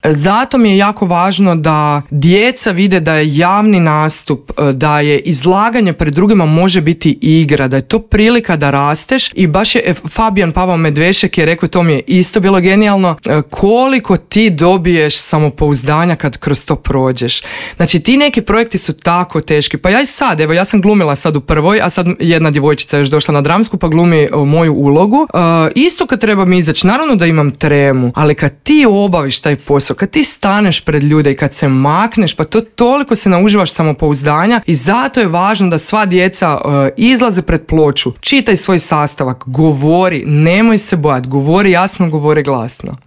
razgovarali smo u Intervjuu Media servisa.